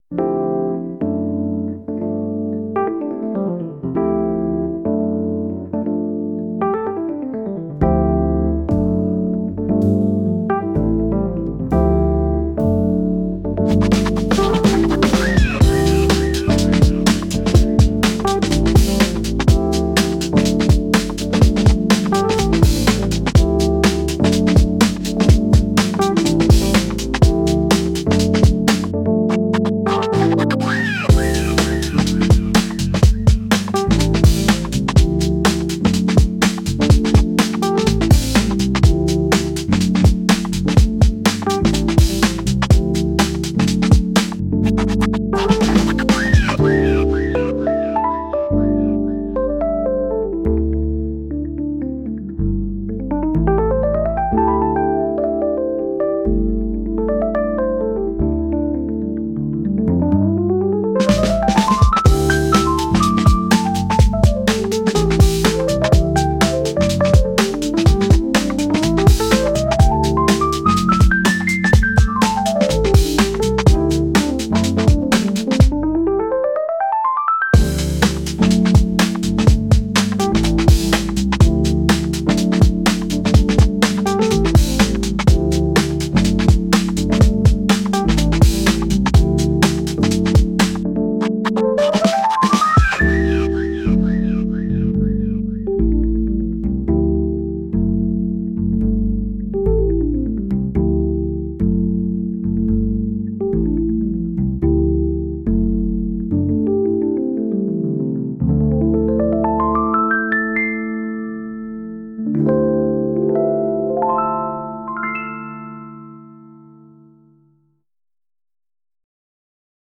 Jazz, Hip Hop
Groovy, Energetic, Soulful
125 BPM